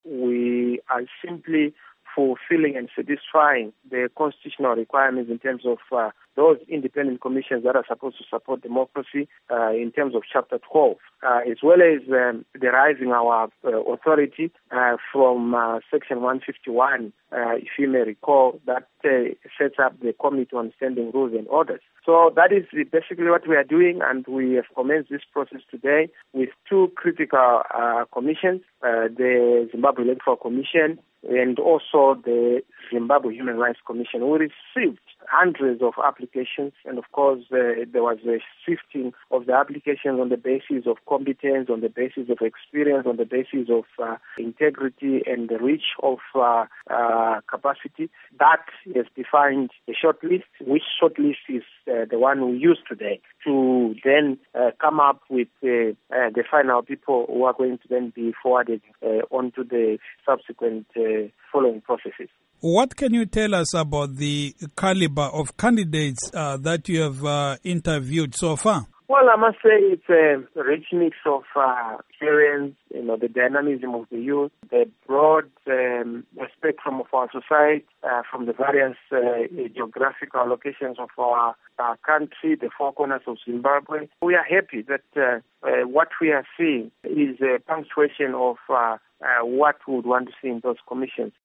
Interview With Nelson Chamisa